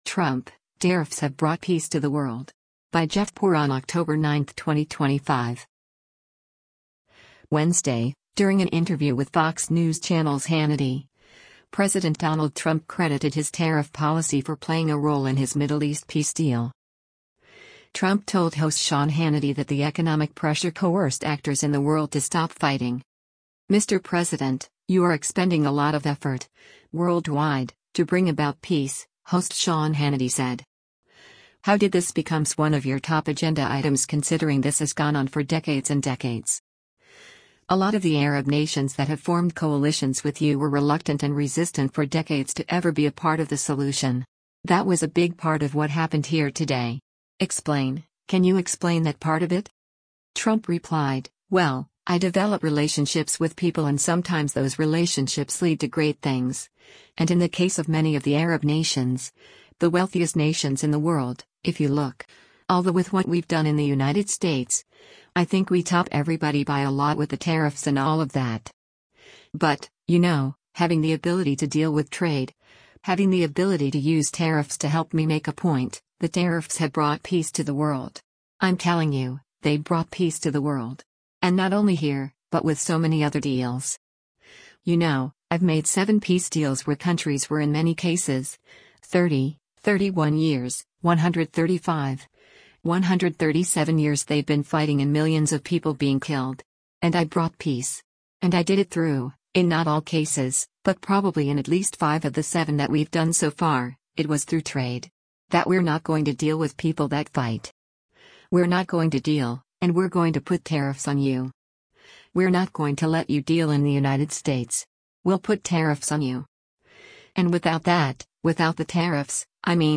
Wednesday, during an interview with Fox News Channel’s “Hannity,” President Donald Trump credited his tariff policy for playing a role in his Middle East peace deal.